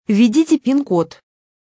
Звук набора пин-кода в банкомате